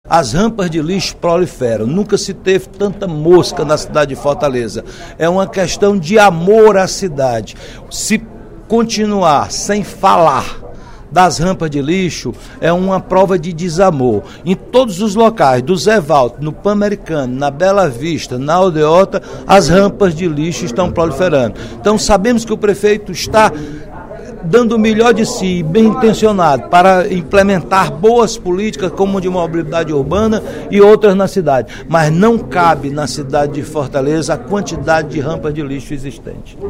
O deputado Roberto Mesquita (PV) apontou preocupação, durante o primeiro expediente da sessão plenária desta quarta-feira (17/02), com a quantidade de lixo acumulada nas ruas de Fortaleza.